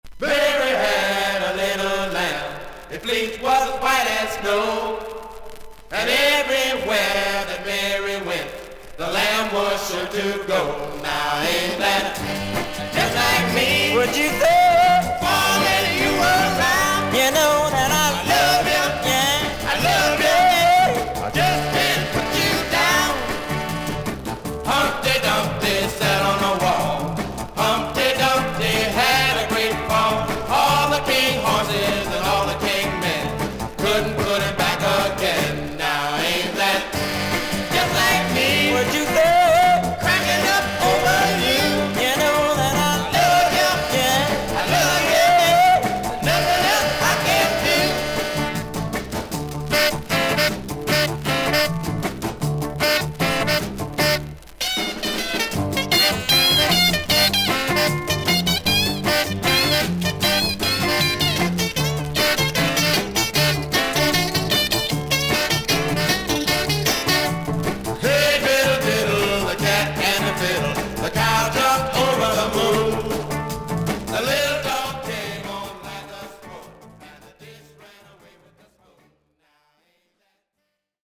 ノベルティR&Bグループ。
初期の作品も良いですがこのアルバムはよりロック色が強くなっていて飽きずに聴けます。
VG++〜VG+ 少々軽いパチノイズの箇所あり。クリアな音です。